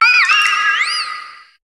Cri de Manaphy dans Pokémon HOME.